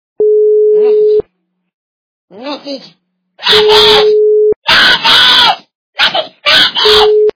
При прослушивании Истерические крики - Message! Message! качество понижено и присутствуют гудки.
Звук Истерические крики - Message! Message!